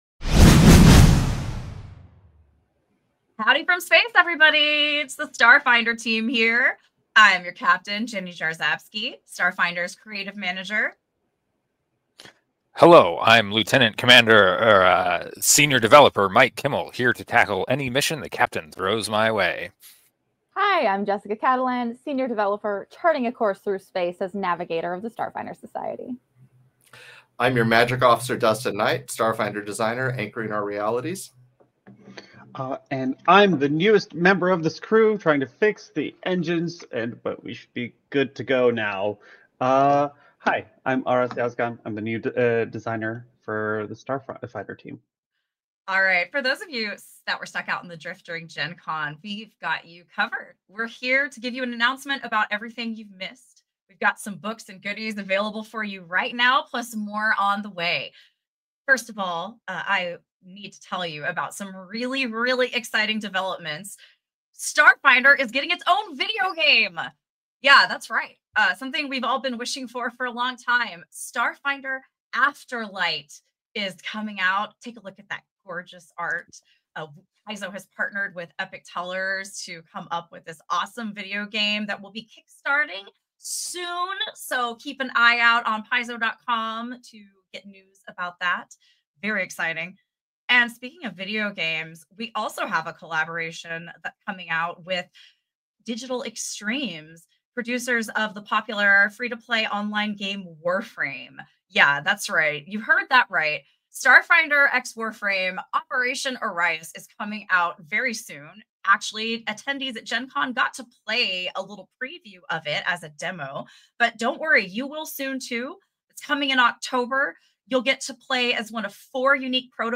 From the Gen Con 2025 Starfinder Launch Panel, shared with permission.